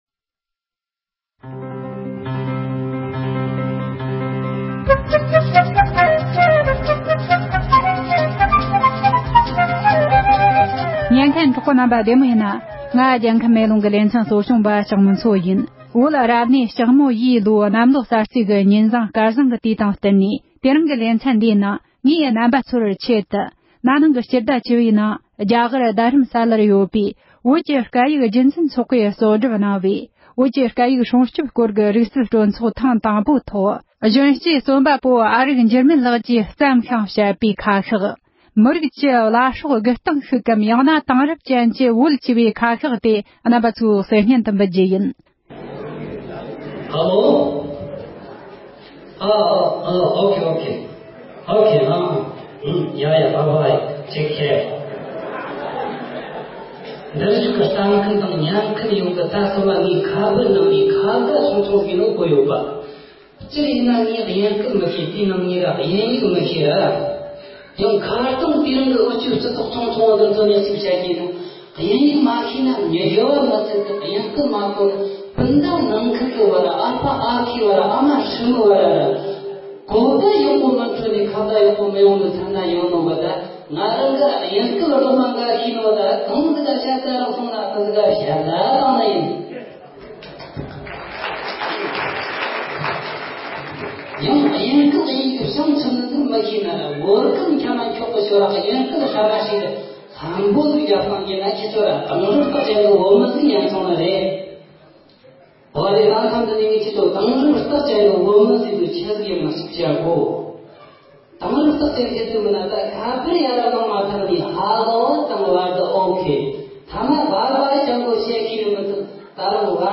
ཁ་ཤགས།
བོད་ཀྱི་ལོ་གསར་གྱི་དུས་དང་བསྟུན་ནས་ན་ནིང་བཙན་བྱོལ་དུ་འཚོགས་པའི་བོད་ཀྱི་སྐད་ཡིག་སྲུང་སྐྱོབ་ཐད་ཀྱི་རིག་རྩལ་སྤྲོ་ཚོགས་ཐོག་མའི་ཐོག